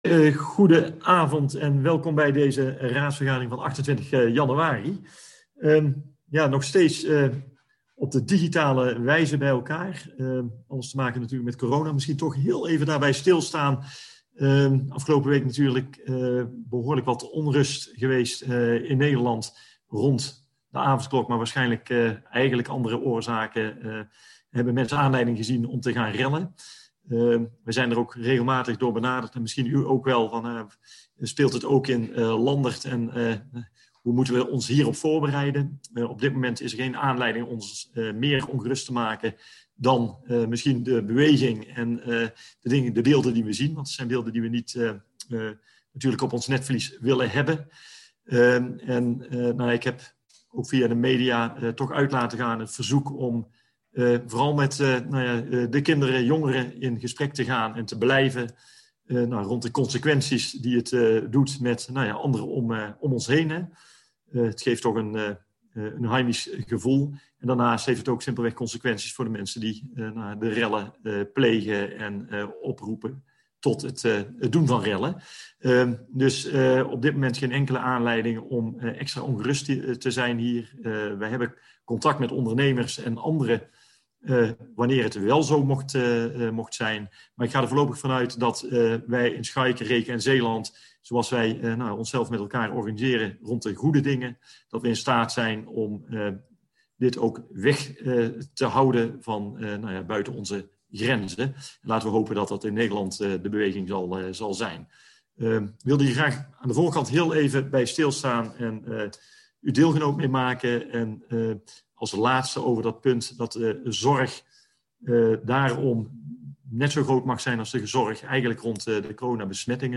Agenda Landerd - Raadsvergadering donderdag 28 januari 2021 19:30 - 22:30 - iBabs Publieksportaal